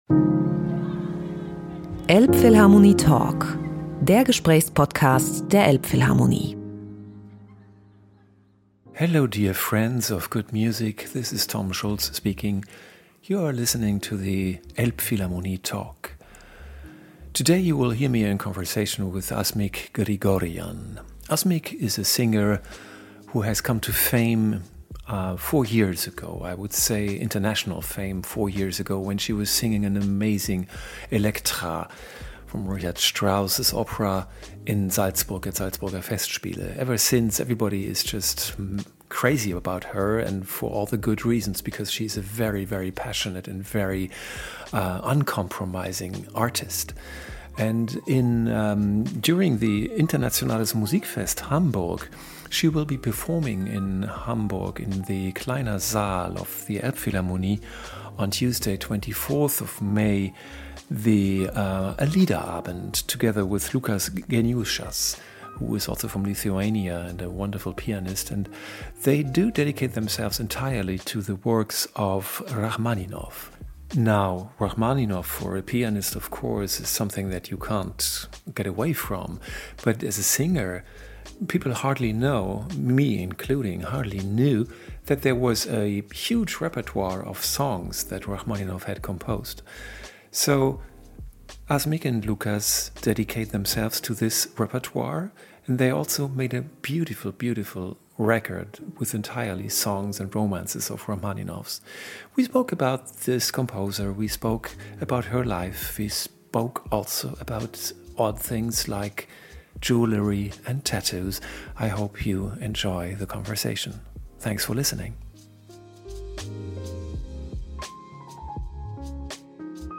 elbphilharmonie-talk-mit-asmik-grigorian-mmp.mp3